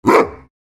Minecraft Version Minecraft Version latest Latest Release | Latest Snapshot latest / assets / minecraft / sounds / mob / wolf / big / bark3.ogg Compare With Compare With Latest Release | Latest Snapshot
bark3.ogg